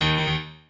piano4_23.ogg